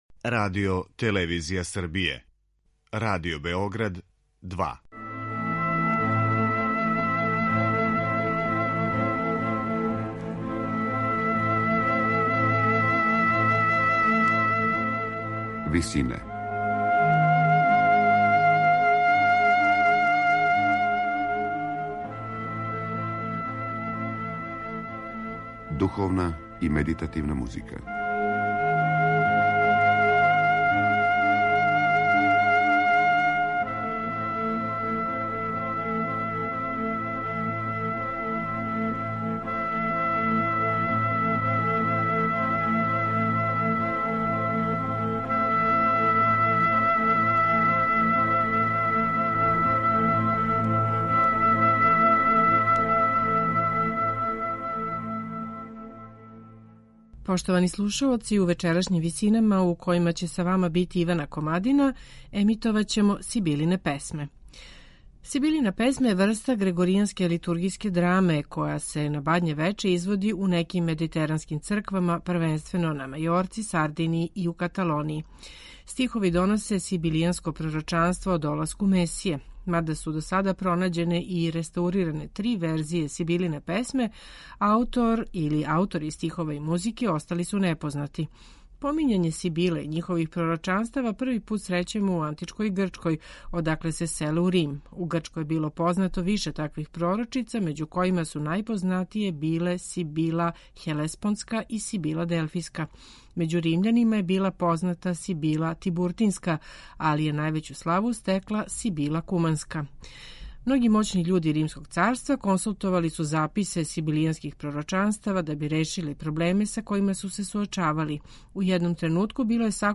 Сибилина песма је врста грегоријанске литургијске драме, која се на Бадње вече изводи у неким медитеранским црквама, првенствено на Мајорки, Сардинији и у Каталонији.